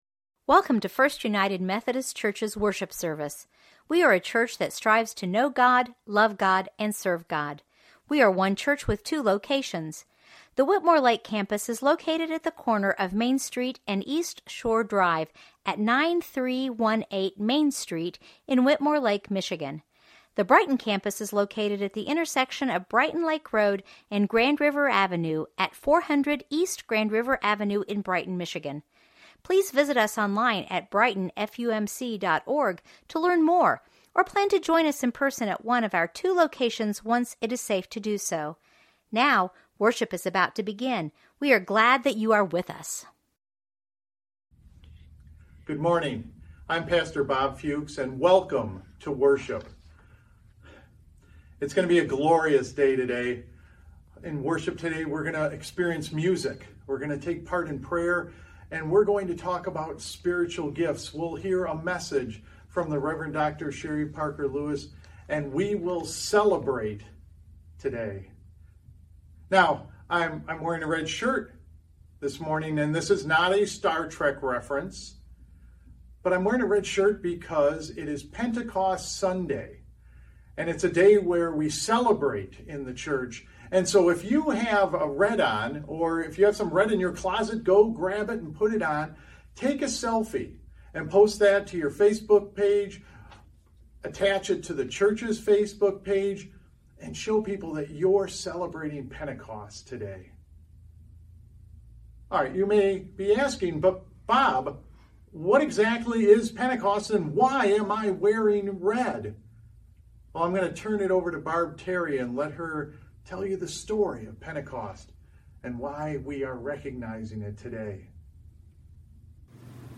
Prayer, Scrirture, and Sermon